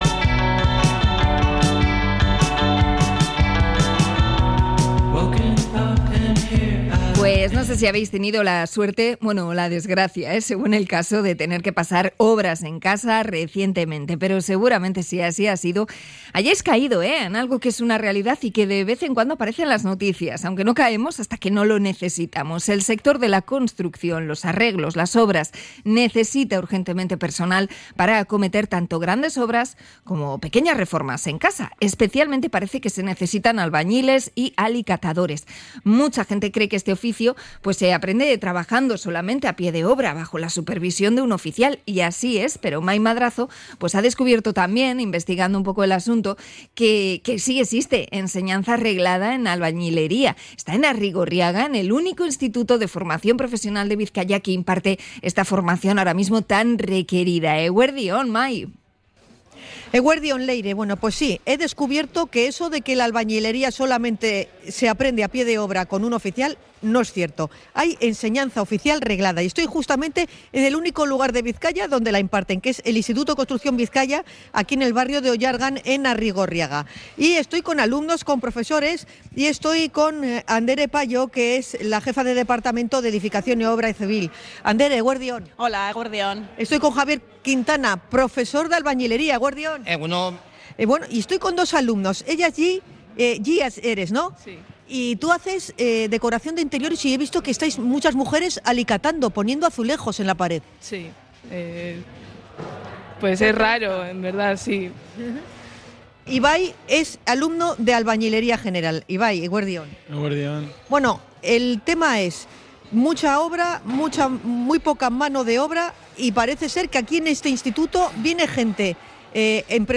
Hablamos con profesores y alumnos del Instituto Construcción Bizkaia